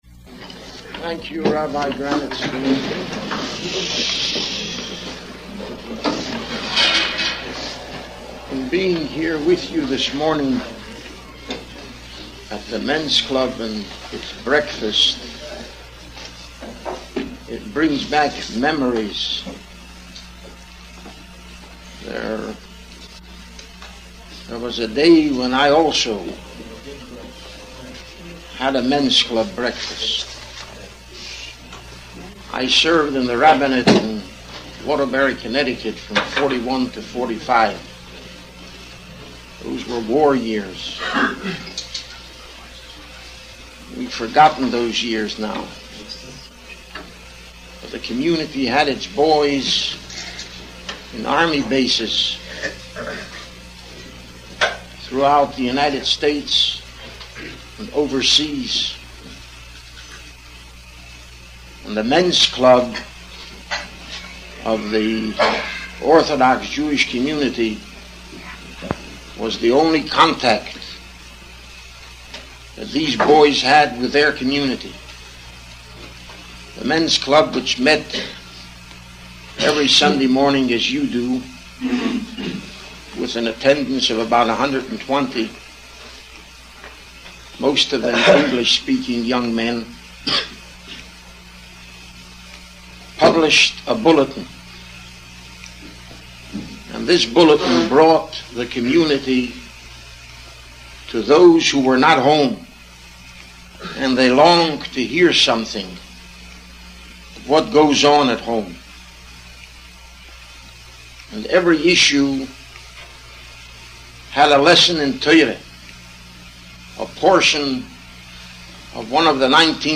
giving a shiur on Minchas Chinuch Sefer Shemos IV.